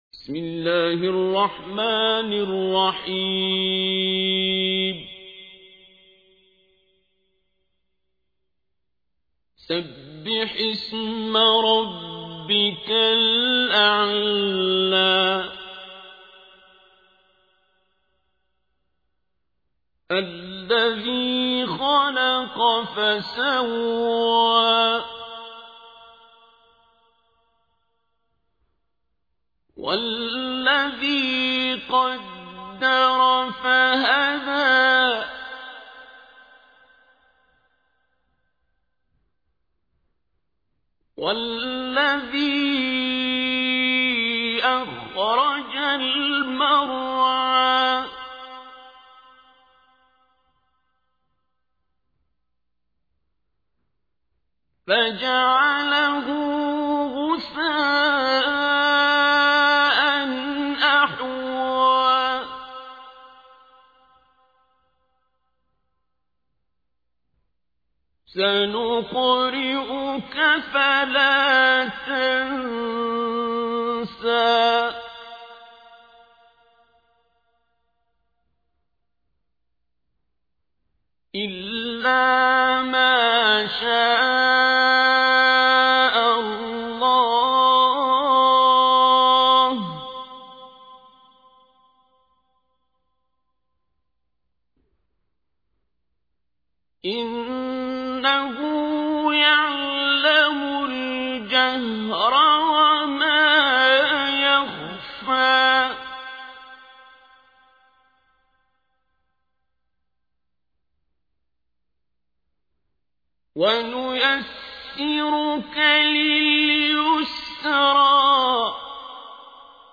تحميل : 87. سورة الأعلى / القارئ عبد الباسط عبد الصمد / القرآن الكريم / موقع يا حسين